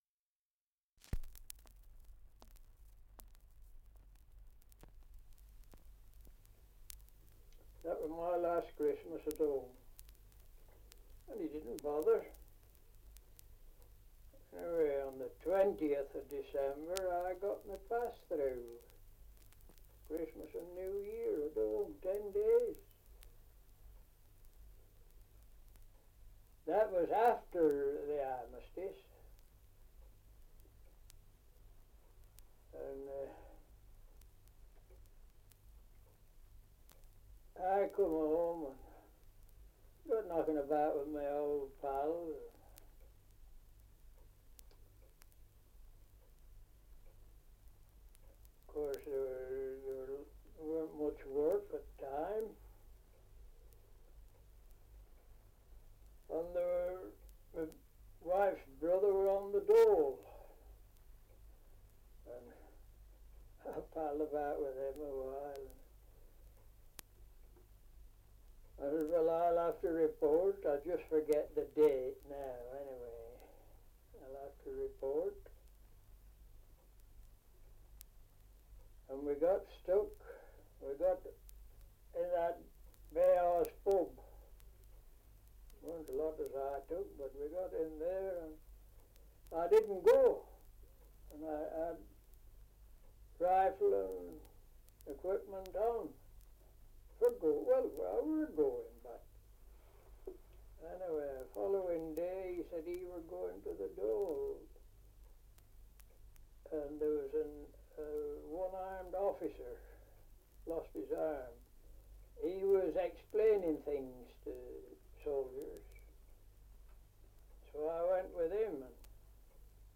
Dialect recording in Whittle-le-Woods, Lancashire
78 r.p.m., cellulose nitrate on aluminium